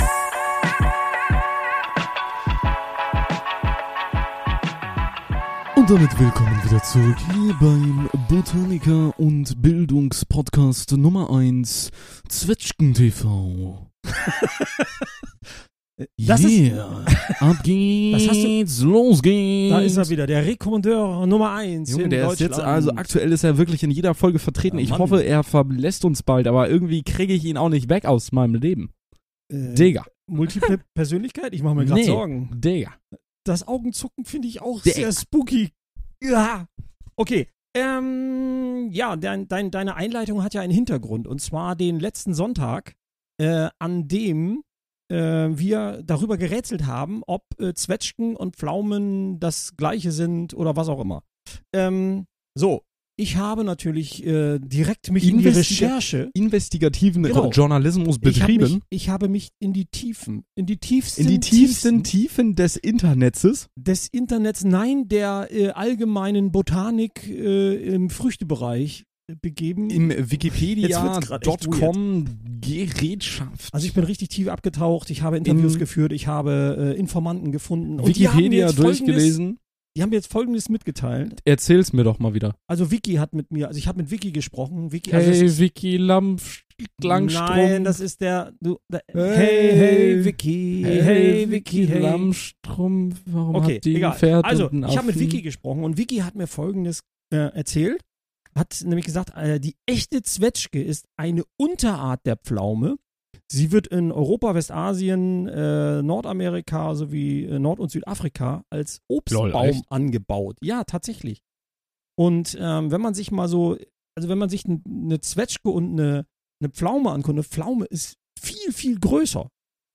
Zwetschgen TV und Einzelsocken im Void. ~ Alt/Klug: Der Vater/Sohn-Podcast Podcast